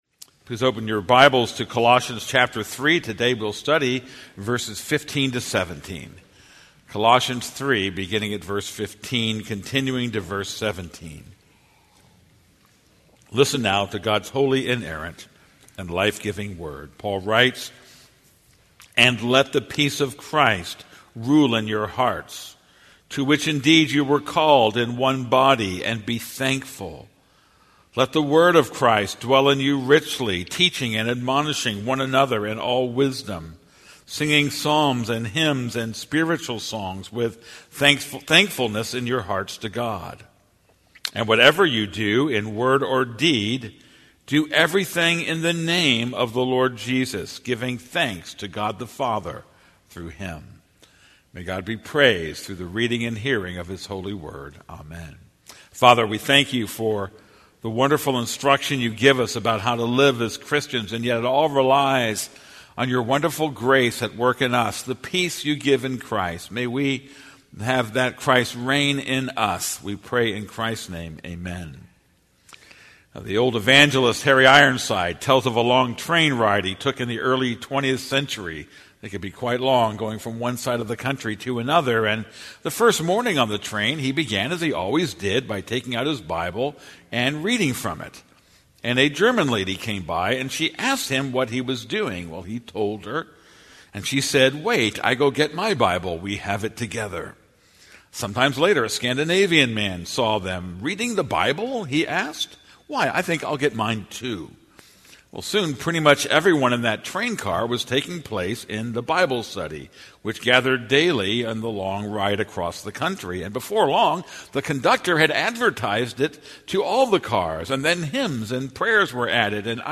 This is a sermon on Colossians 3:15-17.